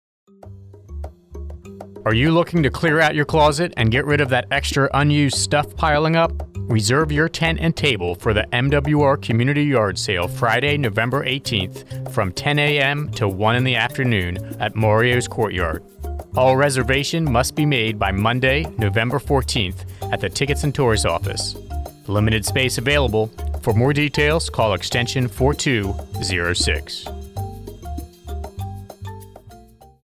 Thirty-second commercial spot highlighting the MWR Bahrain Community Yard Sale, to be aired on AFN Bahrain’s morning and afternoon radio show.